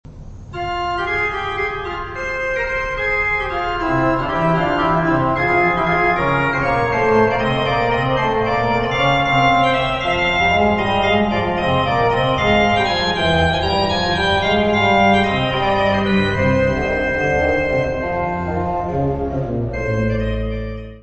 : stereo; 12 cm
Orgão histórico da Abbaye de Saint-Michel en Thiérache
orgão
Music Category/Genre:  Classical Music